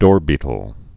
(dôrbētl)